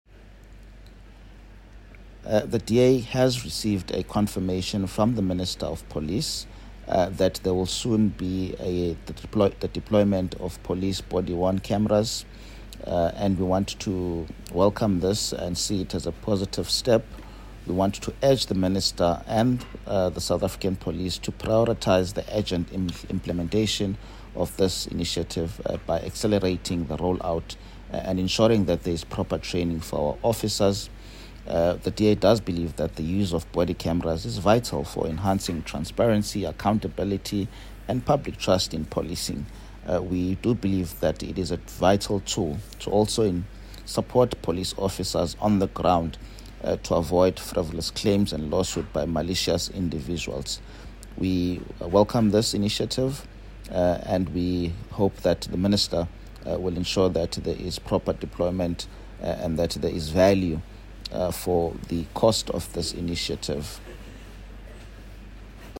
soundbite by Mzamo Billy MP.